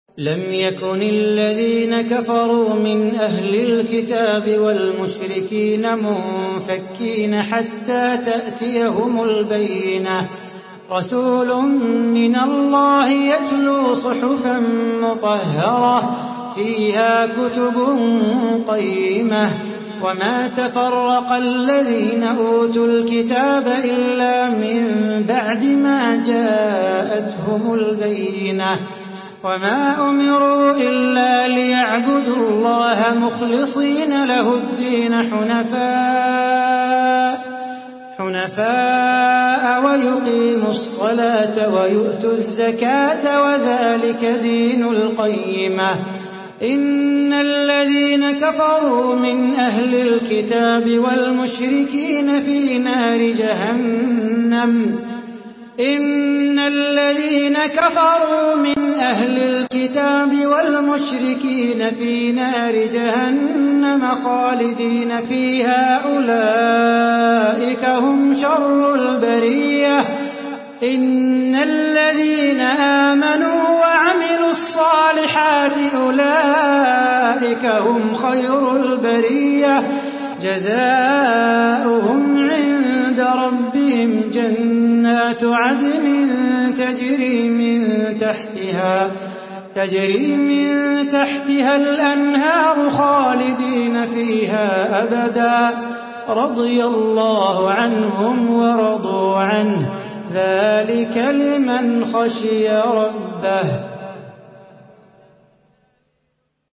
Surah আল-বায়্যিনাহ্ MP3 by Salah Bukhatir in Hafs An Asim narration.
Murattal